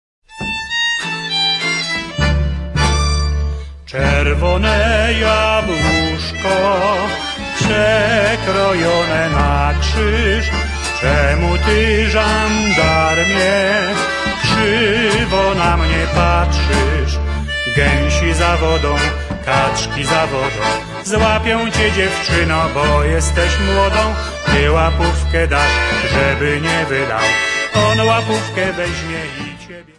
Polish songs